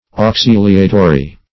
Auxiliatory \Aux*il"ia*to*ry\, a.
auxiliatory.mp3